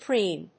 ピーエヌアール